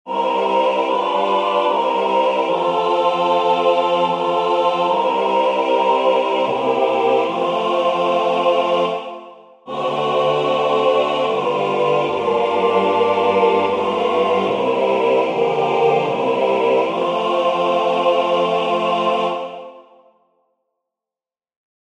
Key written in: F Major
How many parts: 4
Type: Barbershop
Comments: Advent hymn
All Parts mix: